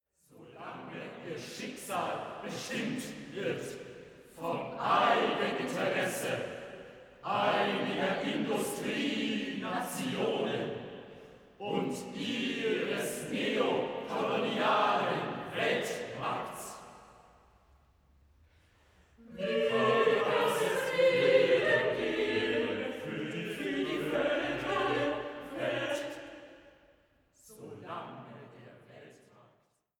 in Mitschnitten der Uraufführungen
Motette